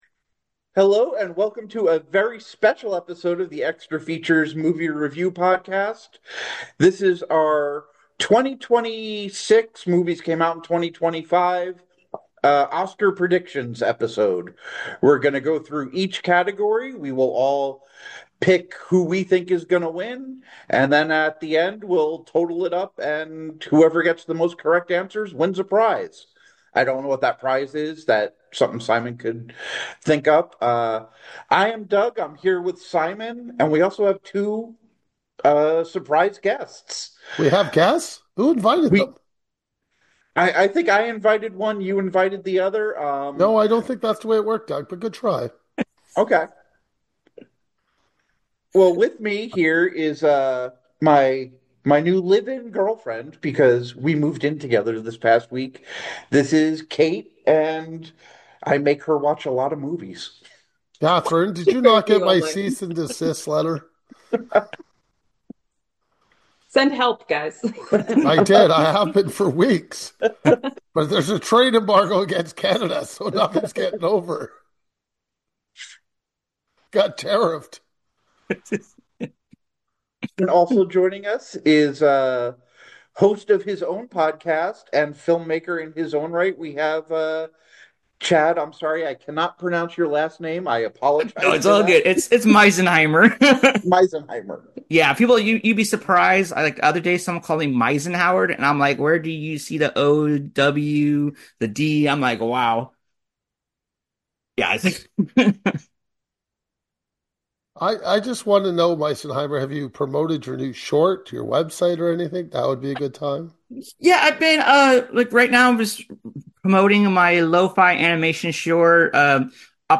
oscar picks 2026 who should win four different guess movies actor actresss director cast discussion laugher